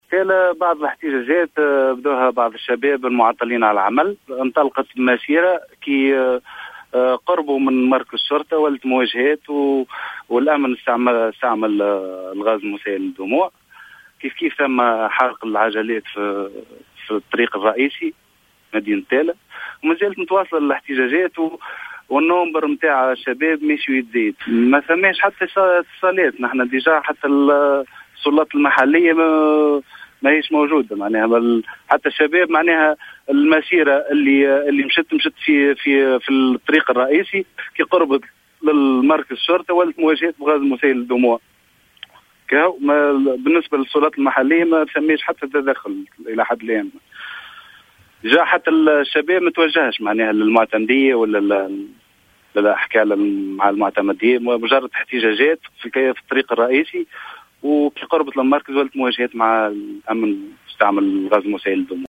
في اتصال هاتفي